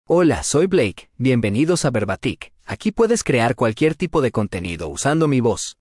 Blake — Male Spanish (United States) AI Voice | TTS, Voice Cloning & Video | Verbatik AI
MaleSpanish (United States)
BlakeMale Spanish AI voice
Voice sample
Male
Blake delivers clear pronunciation with authentic United States Spanish intonation, making your content sound professionally produced.